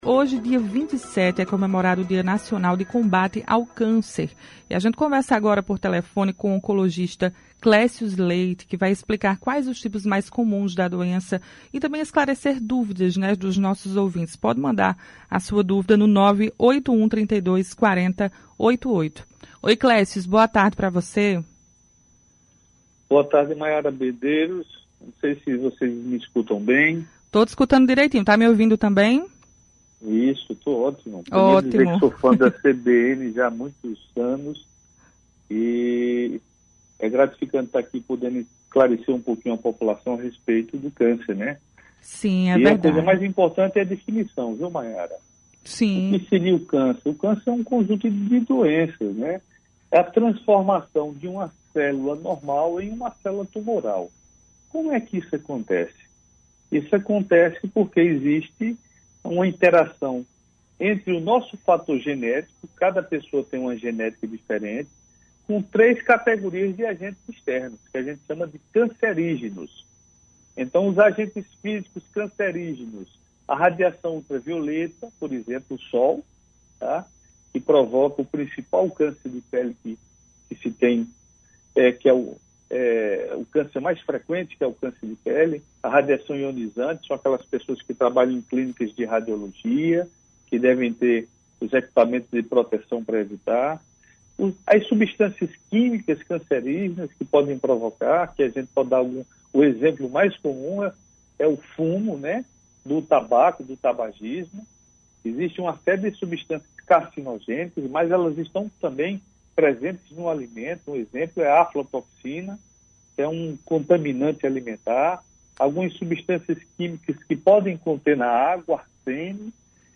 Entrevista: quais são os tipos mais comuns de câncer? Como tratá-los? – CBN Paraíba